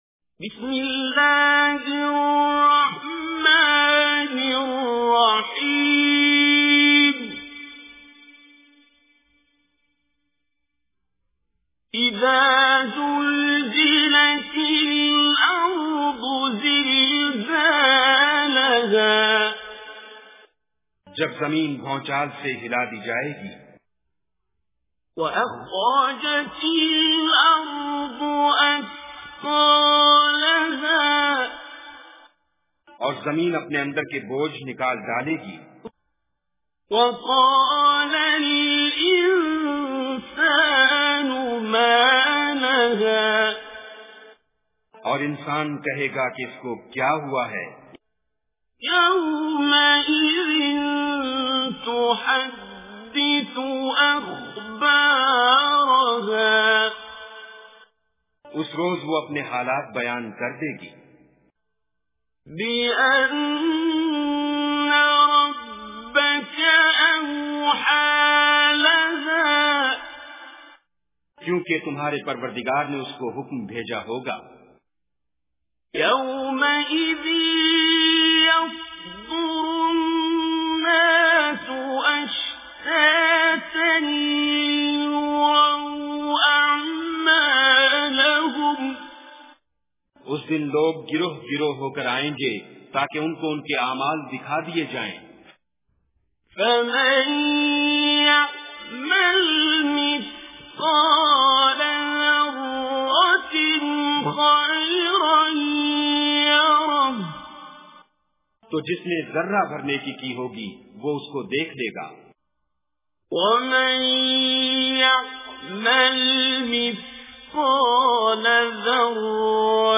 Surah Zalzalah Recitation with Urdu Translation
Surah Zalzalah, listen online mp3 tilawat / recitation in the voice of Qari Abdul Basit As Samad.